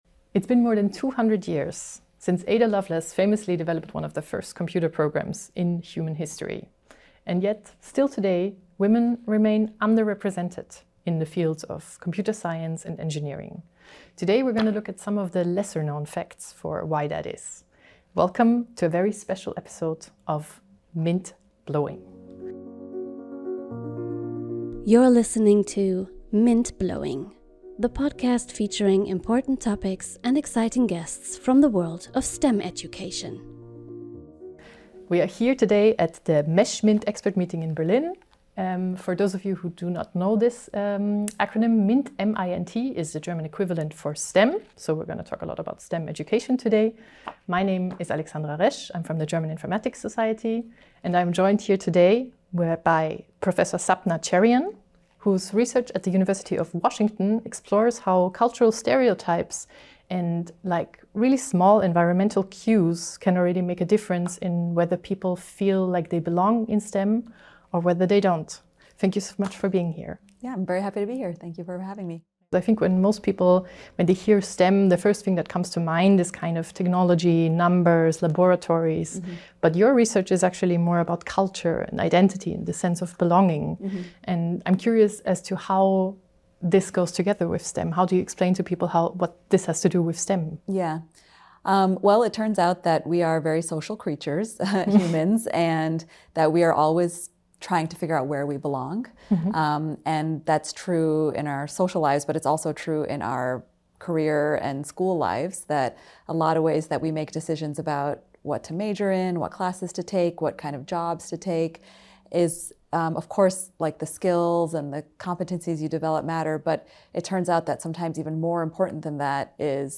Beschreibung vor 9 Monaten Diese Sonderfolge ist in englischer Sprache mit deutschen Untertiteln.